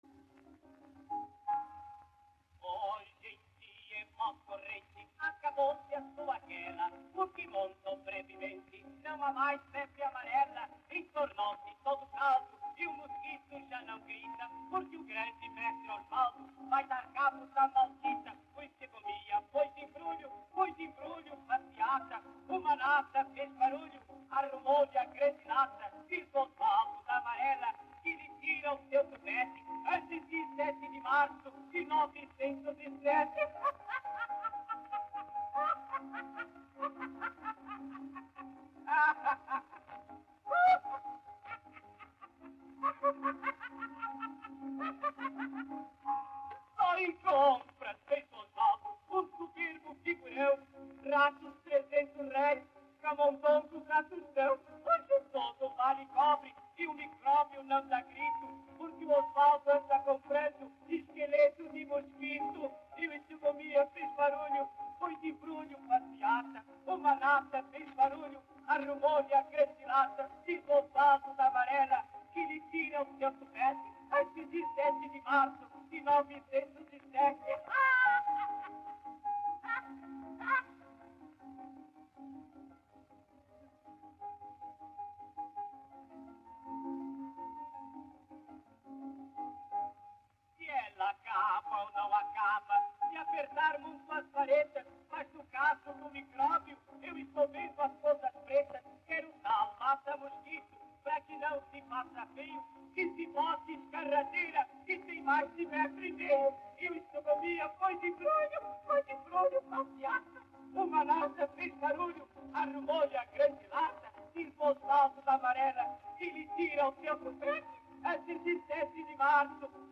Intérprete: Geraldo Magalhães
marchinha-febre-amarela.mp3